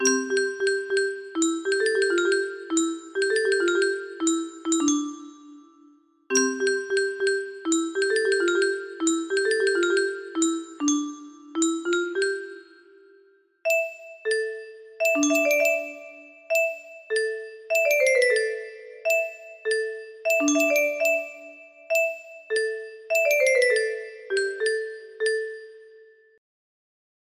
Based on a midi file slower tempo